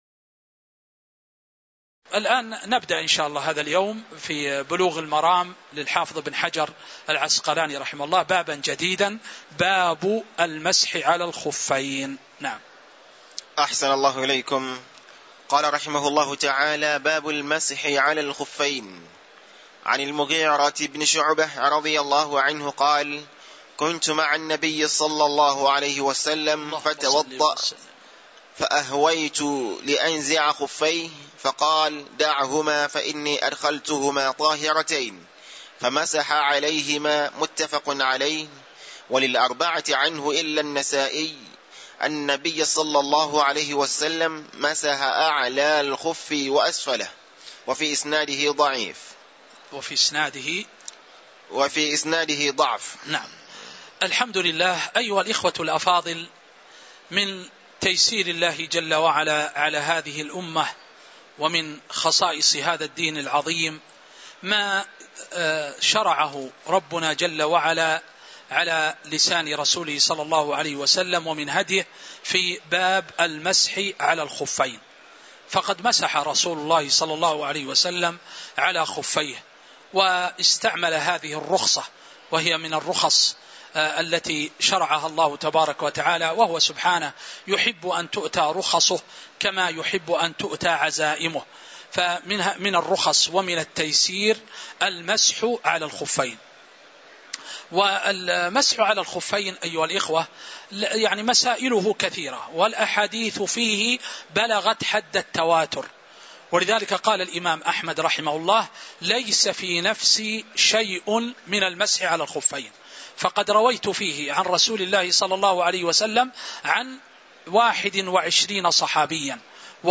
تاريخ النشر ١٦ ذو القعدة ١٤٤٤ هـ المكان: المسجد النبوي الشيخ